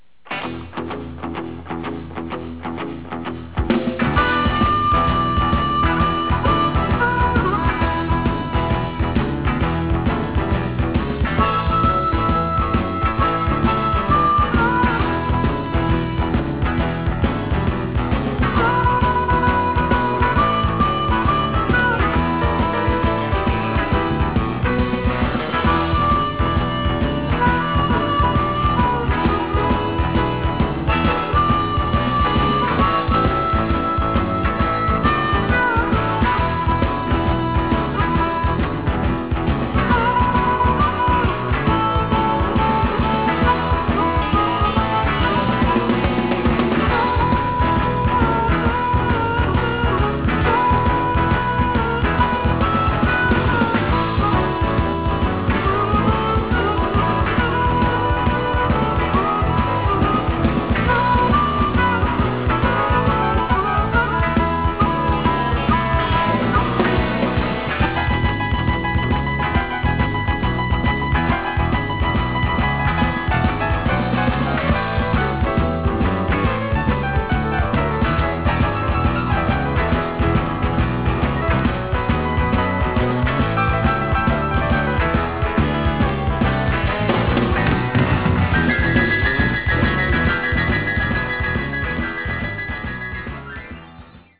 unique, rocking, Cajun-influenced blues style